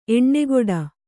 ♪ eṇṇegoḍa